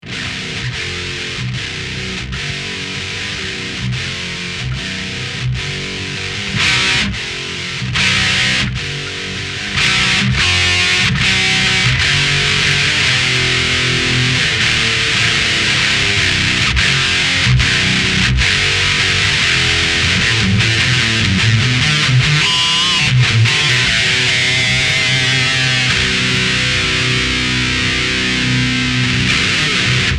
J'en profite pour dire que le préamp est un ADA MP1 et que je le vends !
7BZH_ADA_MP1_metal4.mp3